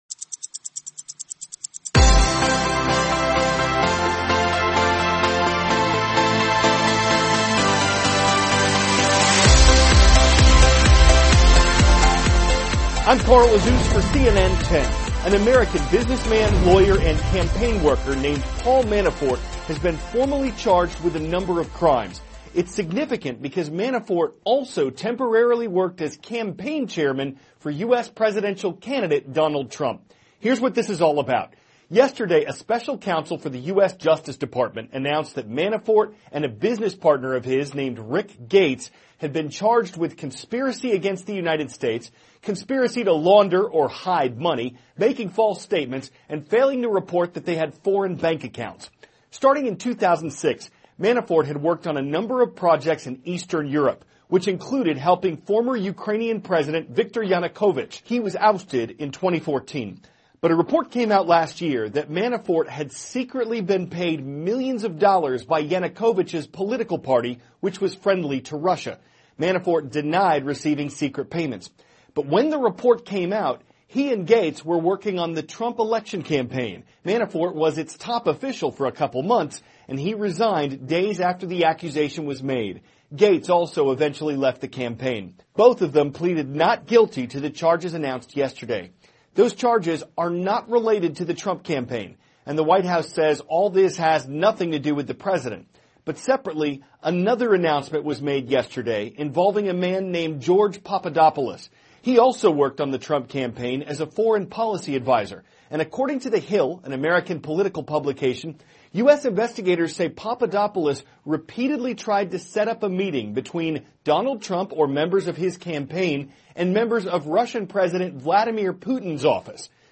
CARL AZUZ, cnn 10 ANCHOR: I`m Carl Azuz for cnn 10.